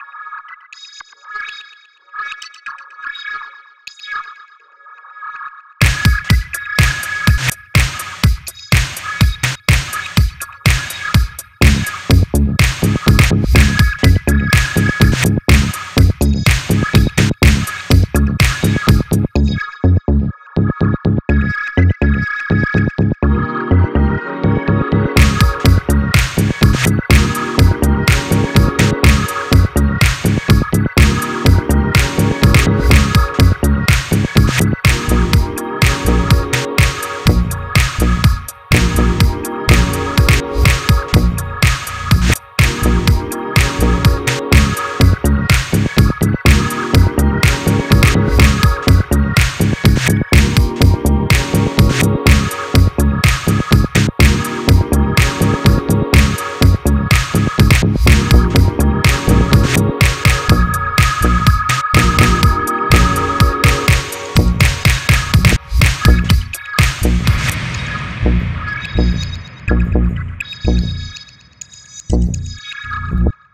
That was a fun groove man and dope reverse effect you have on the drums!